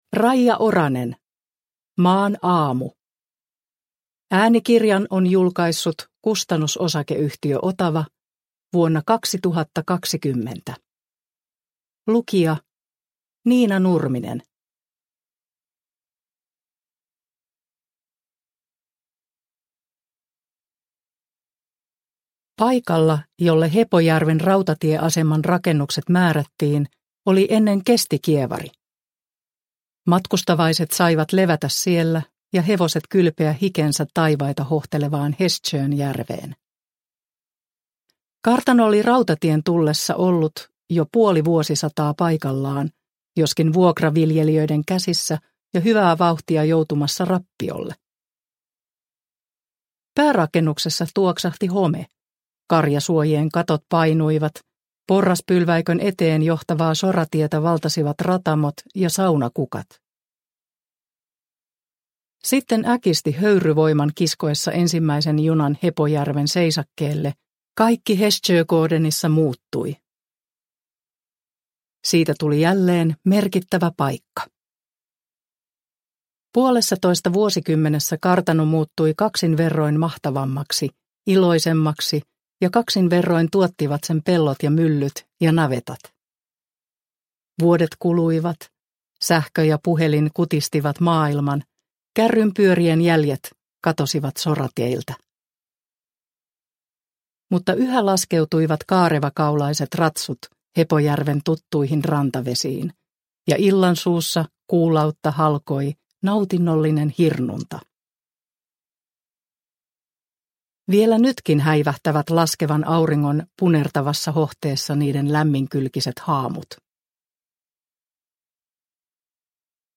Maan aamu – Ljudbok – Laddas ner